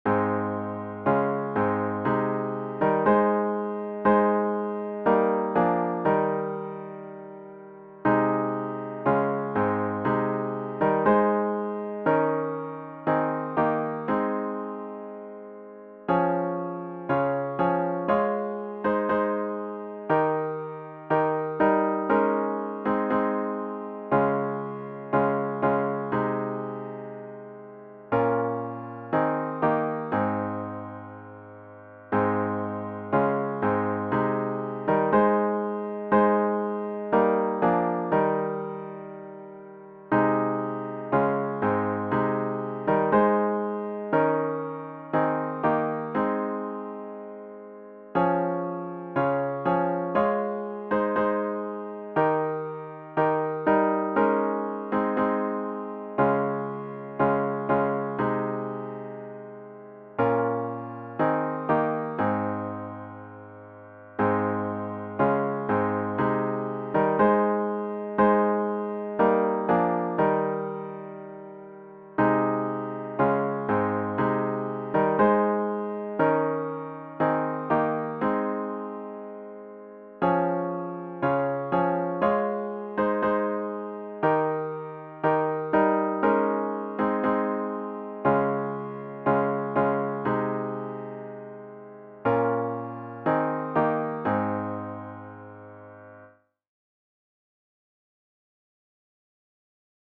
CLOSING HYMN   “More Love to Thee, O Christ”   GtG 828
zz-828-More-Love-to-Thee-O-Christ-piano-only.mp3